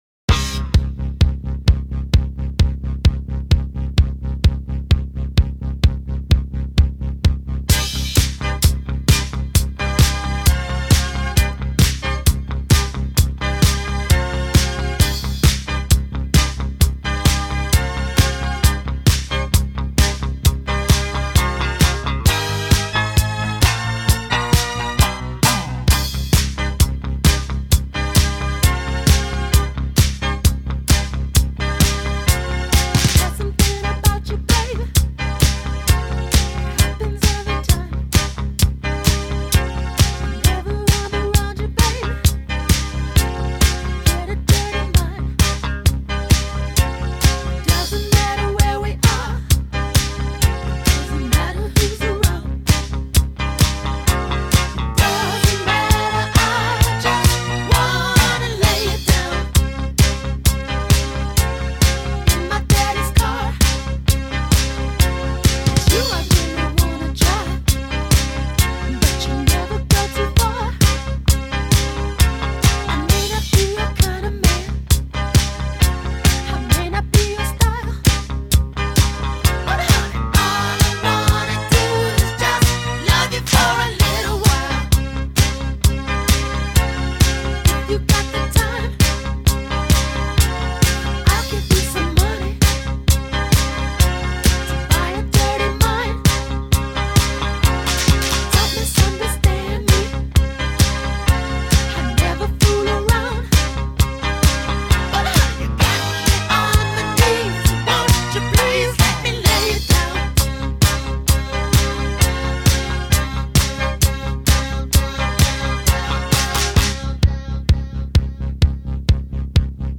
a mix pak of Funk